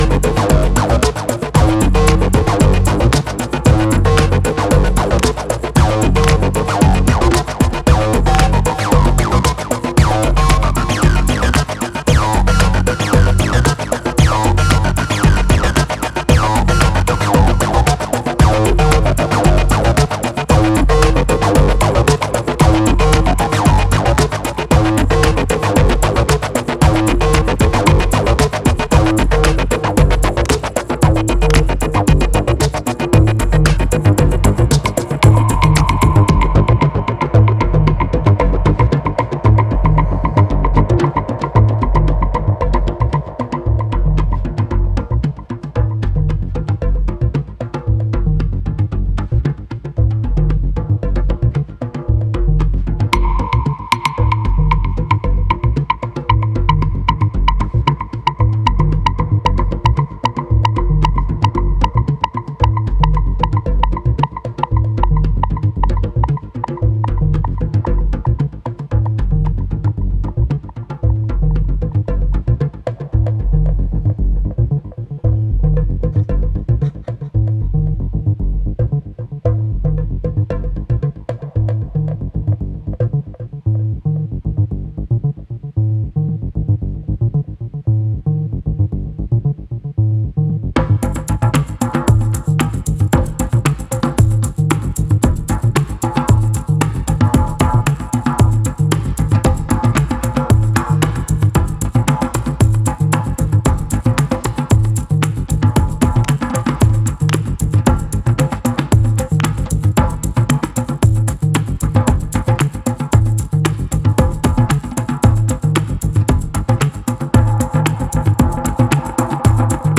ラテン、アフロ、ダブ、ダンスホール、アシッドなど様々なスタイルに入り混じるバラエティに富んだ5曲を収録しています。